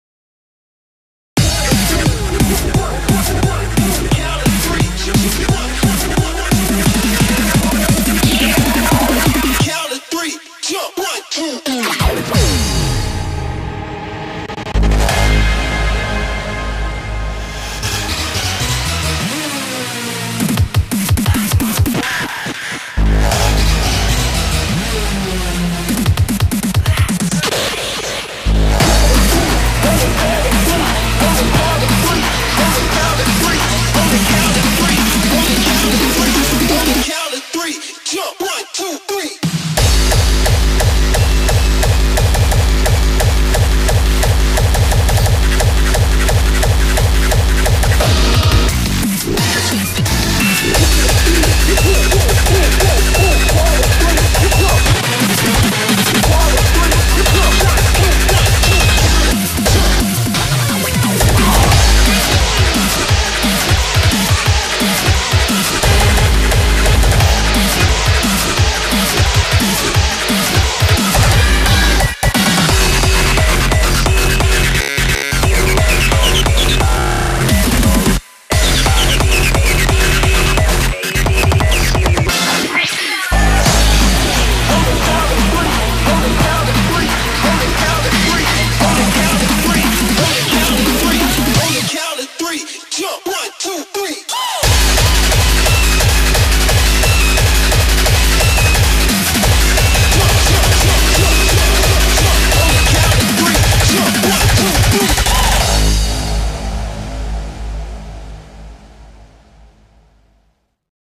BPM175
MP3 QualityMusic Cut